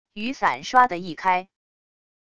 雨伞刷的一开wav音频